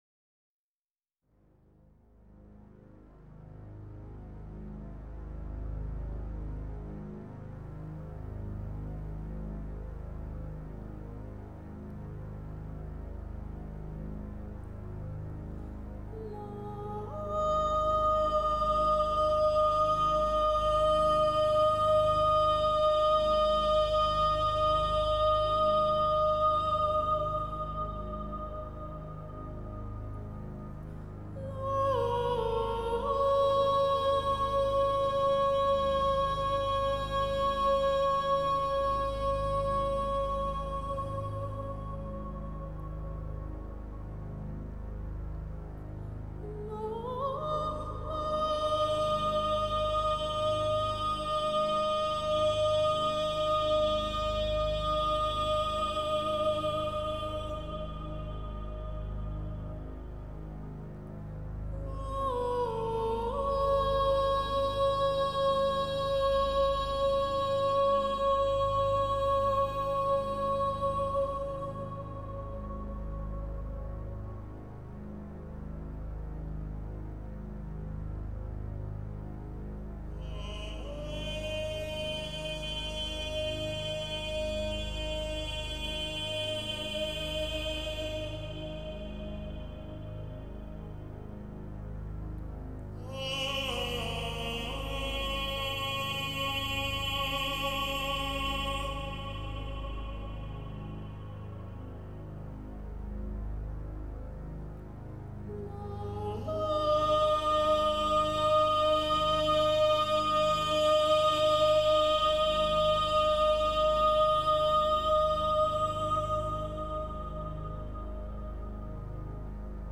Genre: Fado, Ballad